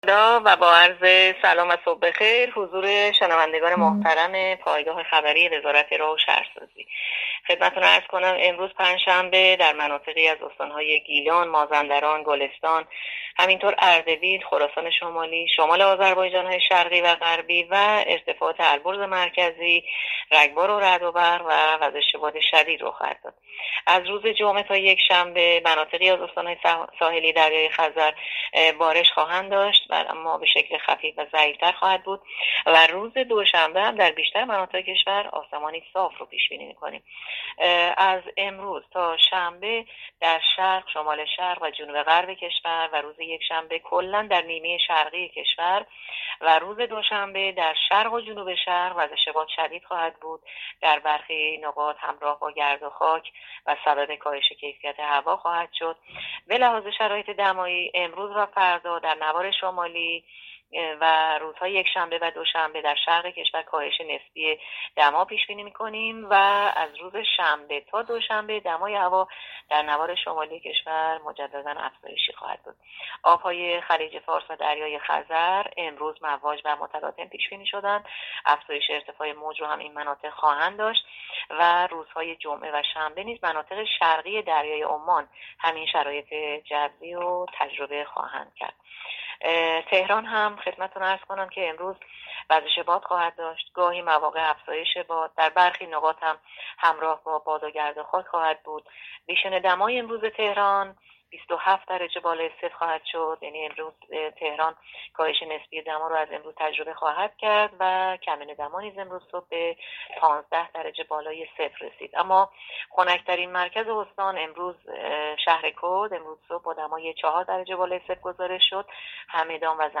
گزارش رادیو اینترنتی پایگاه‌ خبری از آخرین وضعیت آب‌وهوای دهم مهر؛